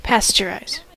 pasteurize: Wikimedia Commons US English Pronunciations
En-us-pasteurize.WAV